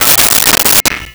Pot Lid 01
Pot Lid 01.wav